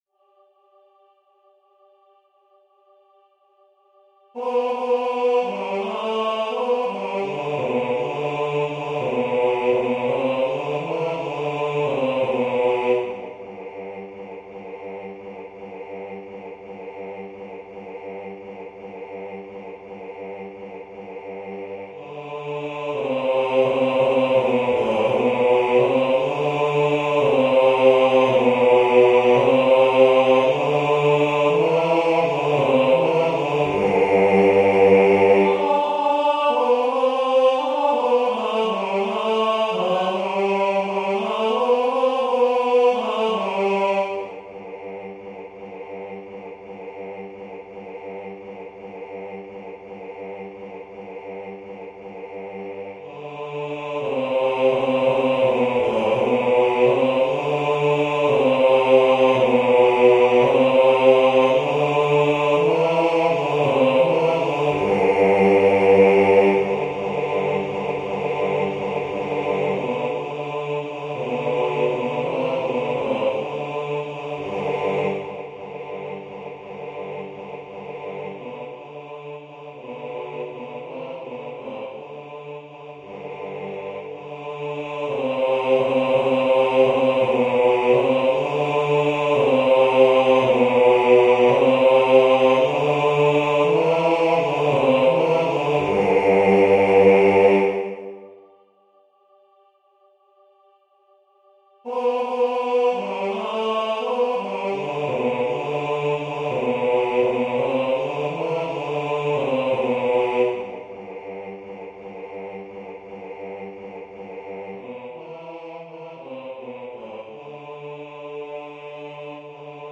Bass
Sussex_Carol_Bass.mp3